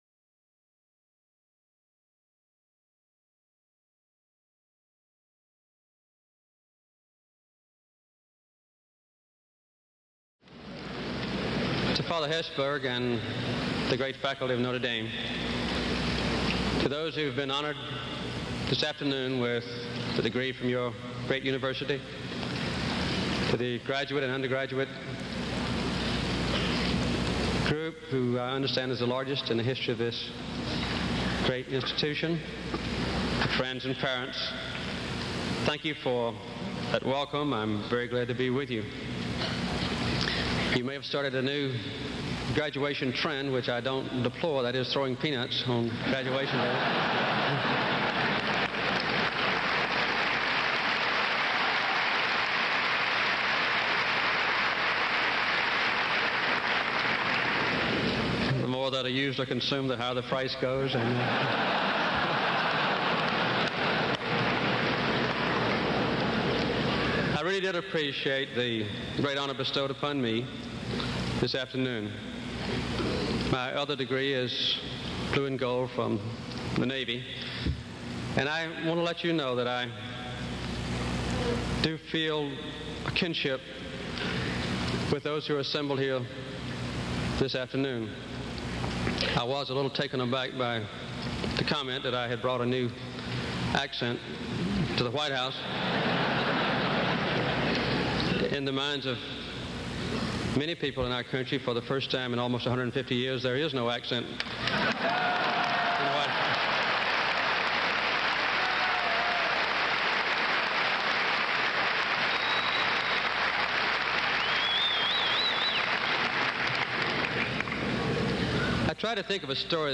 May 22, 1977: University of Notre Dame Commencement